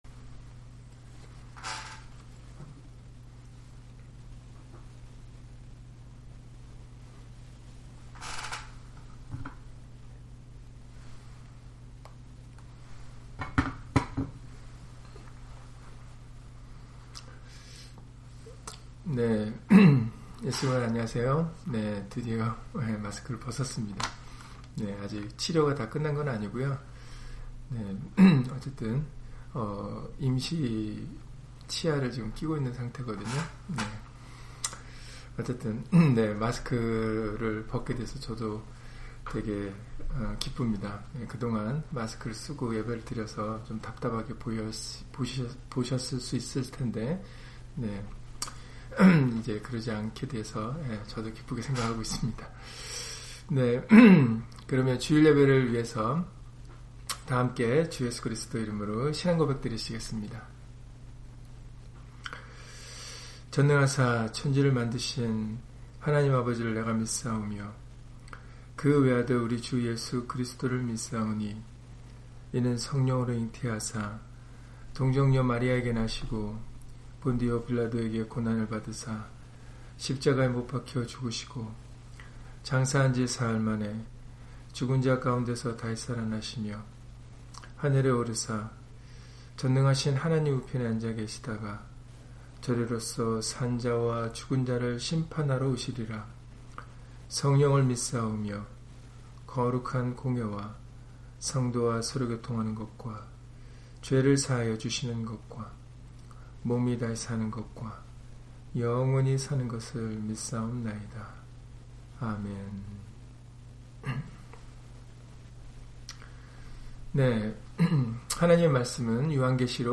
요한계시록 22장 20절 [내가 진실로 속히 오리라] - 주일/수요예배 설교 - 주 예수 그리스도 이름 예배당